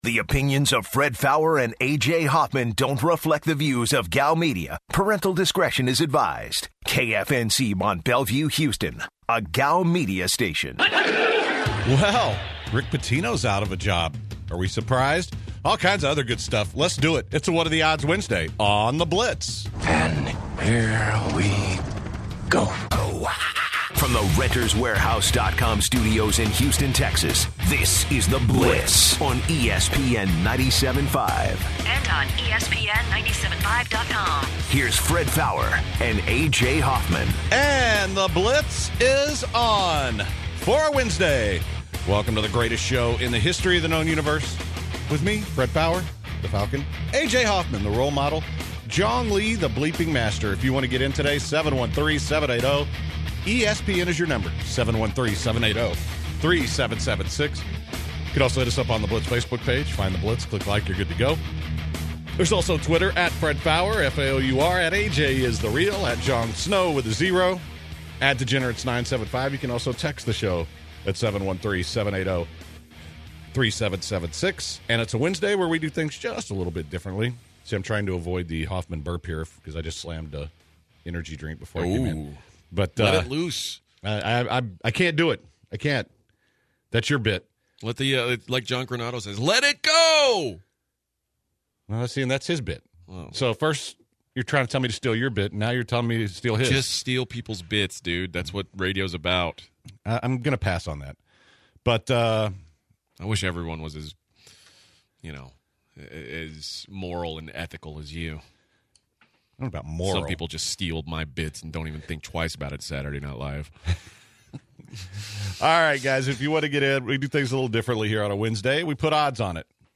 On the first hour the guys take What are the odds Wednesday calls, they talk Hiesmann, Astros baseball, the Cash me outside girl and they touch on the anthem protests as well.